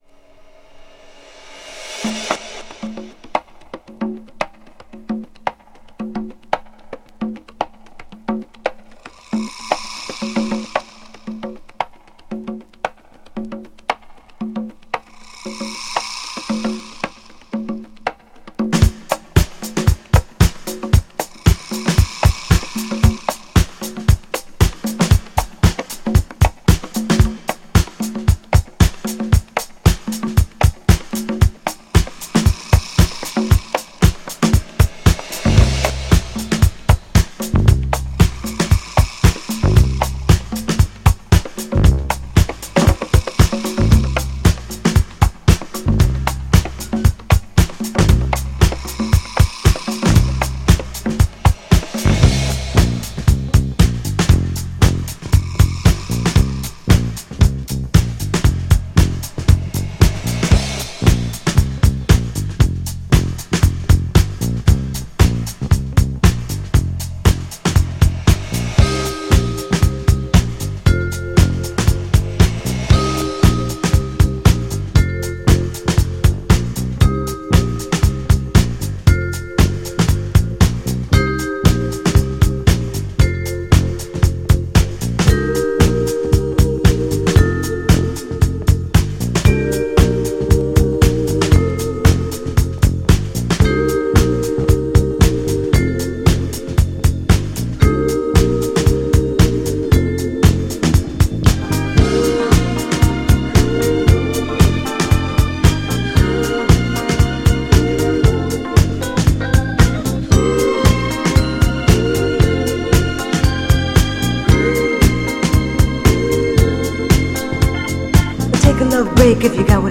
GENRE Dance Classic
BPM 121〜125BPM
# INSTRUMENTAL
# エフェクトがすごい # コズミック # ダビー